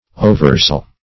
Search Result for " oversoul" : The Collaborative International Dictionary of English v.0.48: Oversoul \O"ver*soul`\, n. The all-containing soul.